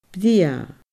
brèagha /brʲiə.ə/